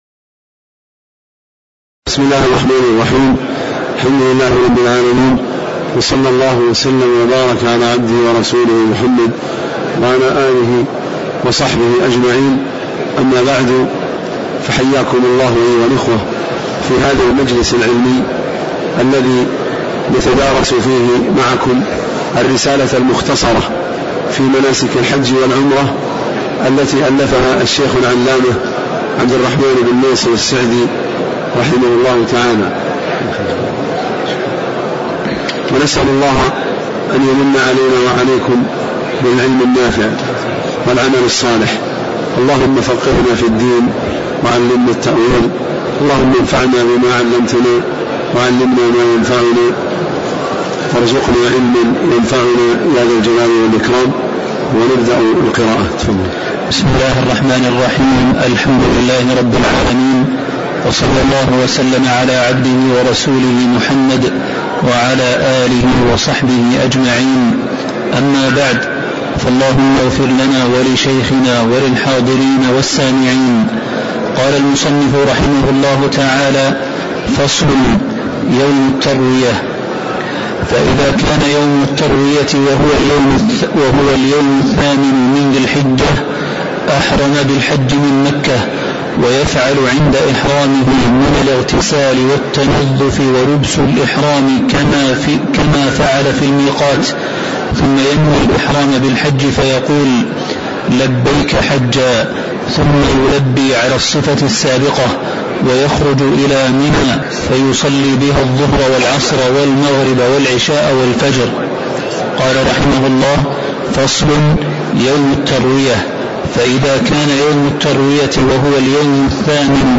تاريخ النشر ٢٣ ذو القعدة ١٤٣٩ هـ المكان: المسجد النبوي الشيخ